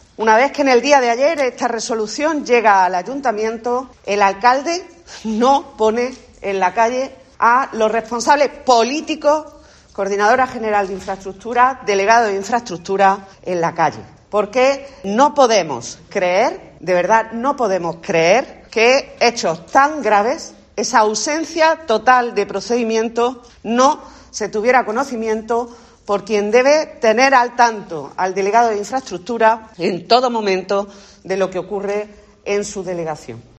Escucha a Alba Doblas, concejala de Izquierda Unida sobre la resolución de Fiscalía